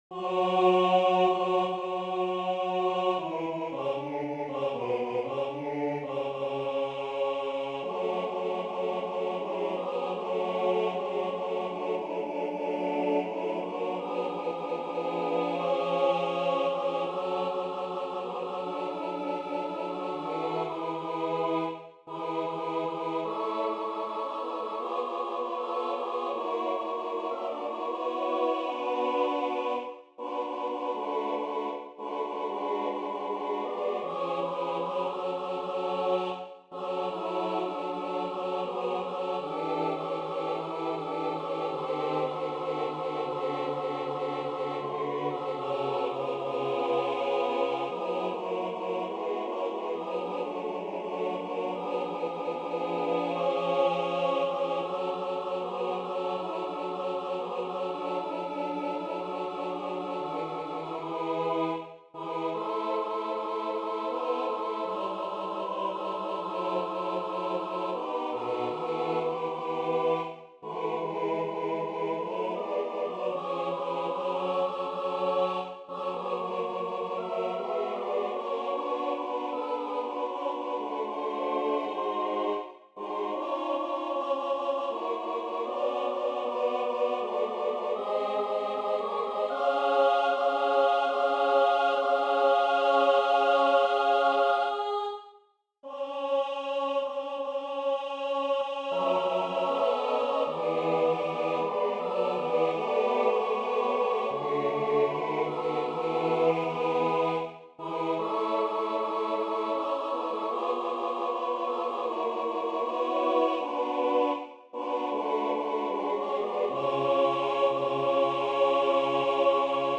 Апостольский символ веры для смешанного хора, a cappella